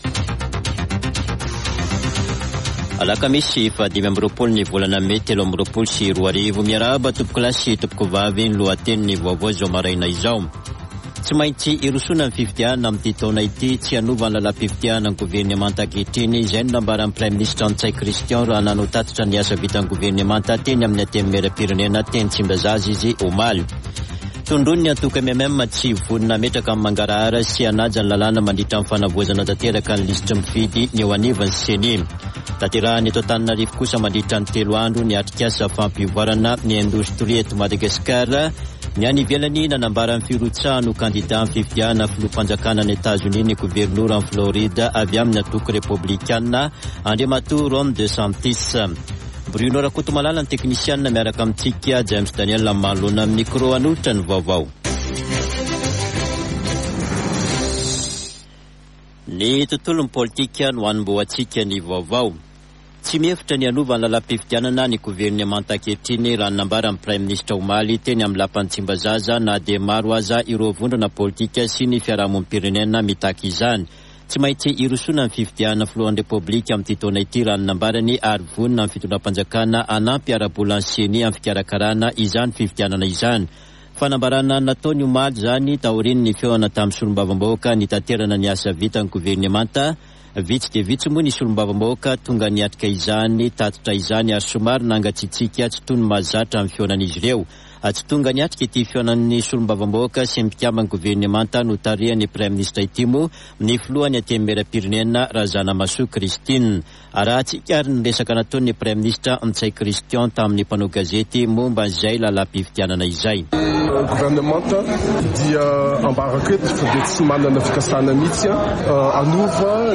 [Vaovao maraina] Alakamisy 25 mey 2023